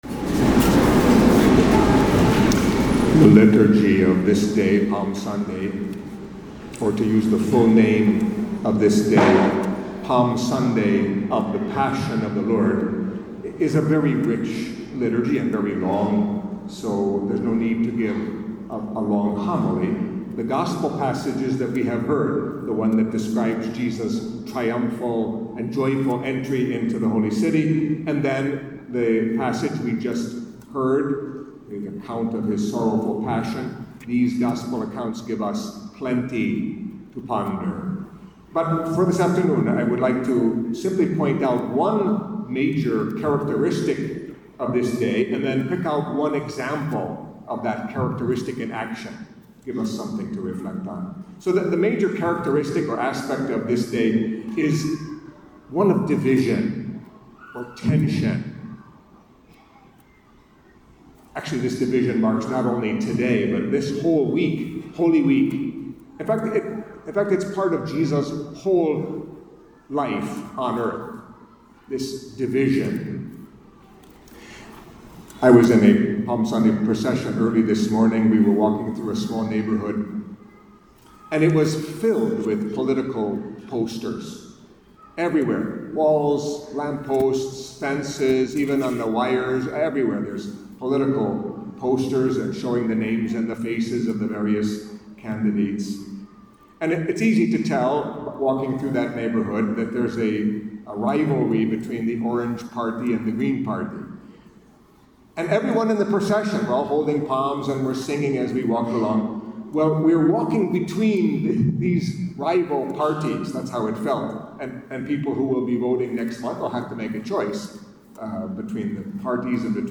Catholic Mass homily for Palm Sunday of the Passion of the Lord